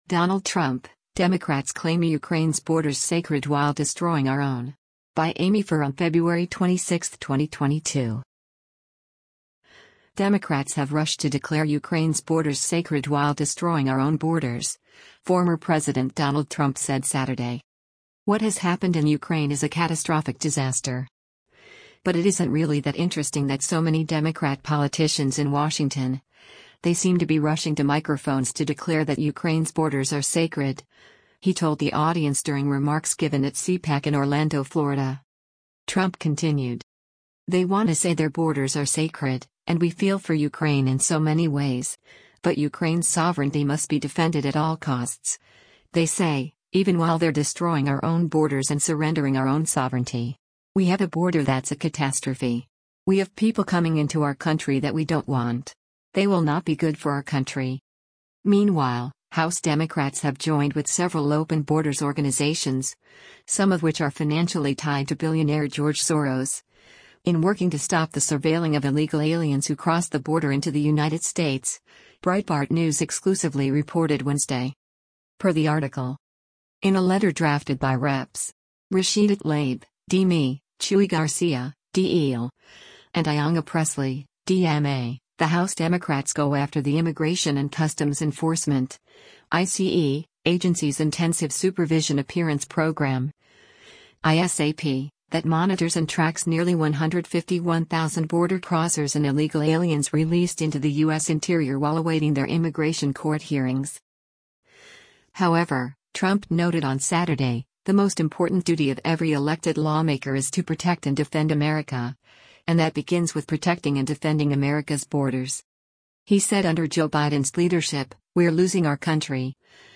“What has happened in Ukraine is a catastrophic disaster. But it isn’t really that interesting that so many Democrat politicians in Washington, they seem to be rushing to microphones to declare that Ukraine’s borders are sacred,” he told the audience during remarks given at CPAC in Orlando, Florida.